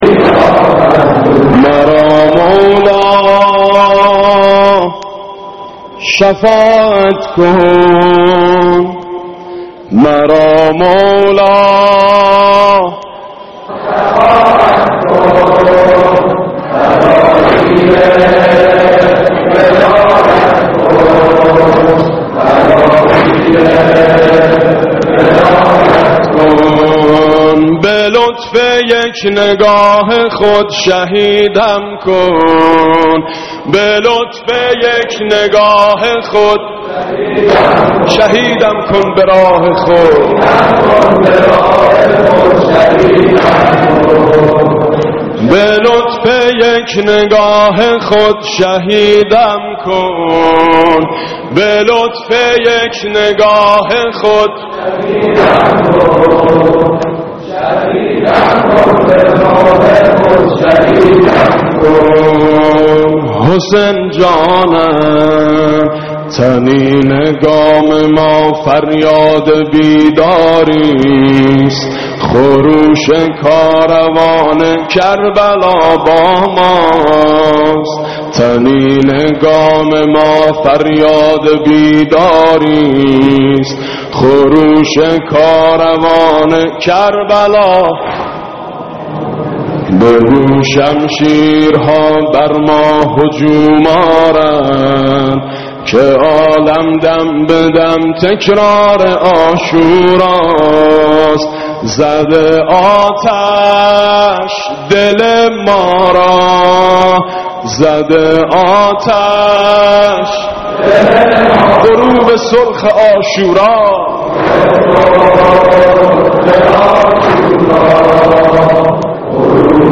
maddahi-193.mp3